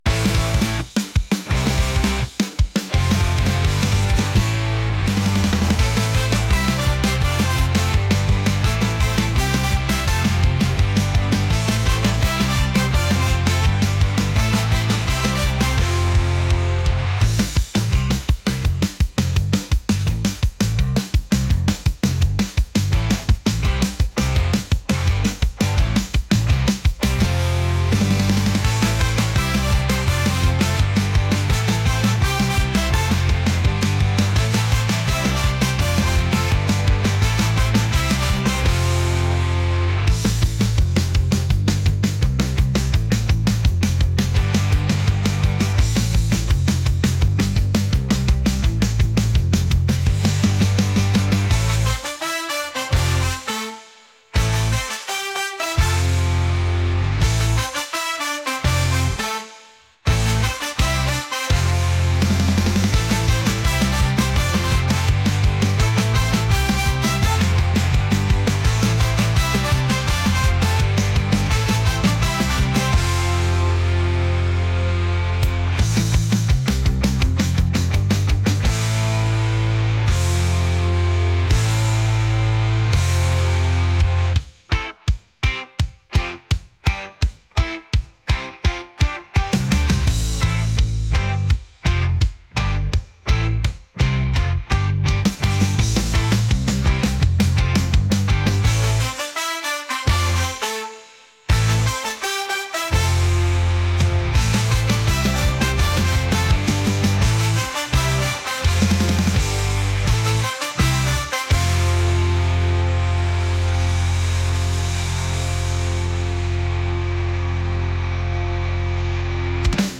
energetic | ska | punk